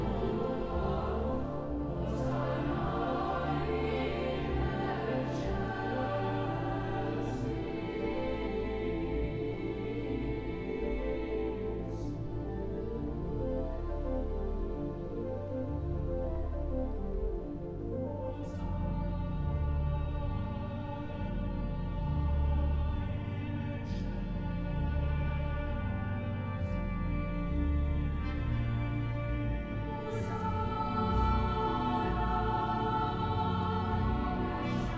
Classical Sacred Choral
Жанр: Классика